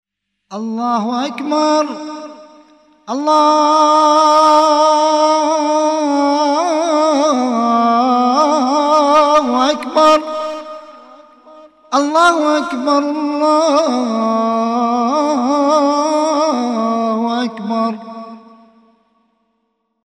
الأذان
تكبيرات